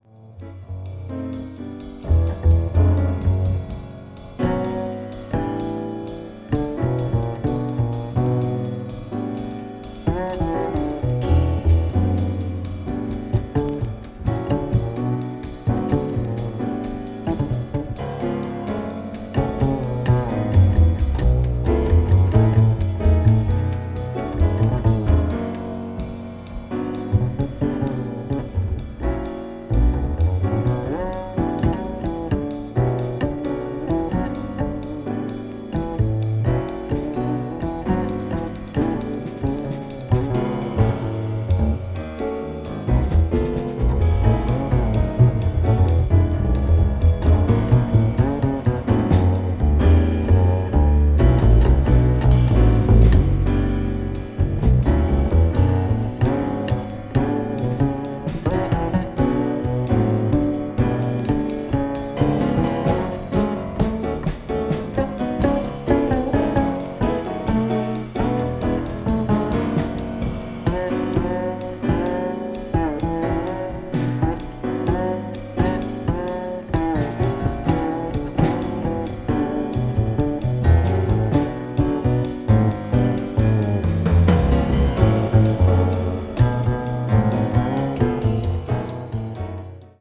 わずかにチリノイズが入る箇所あり